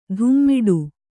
♪ dhummiḍu